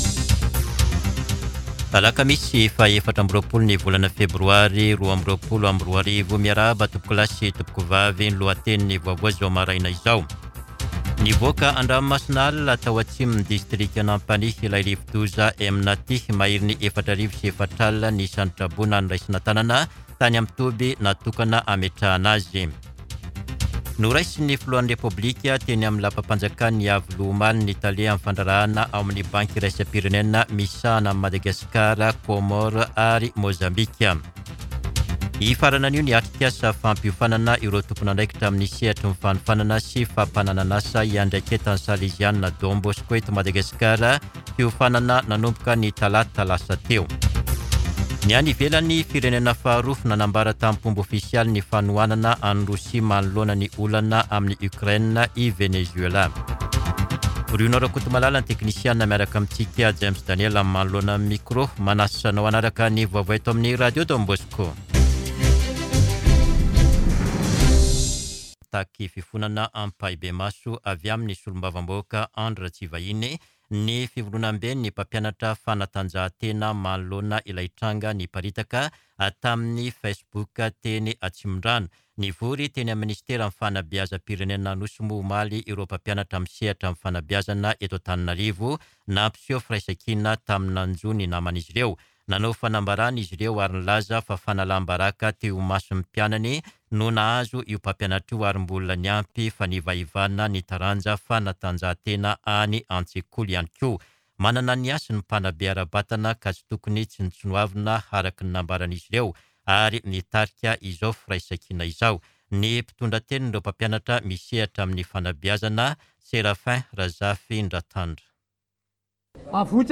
[Vaovao maraina] Alakamisy 24 febroary 2022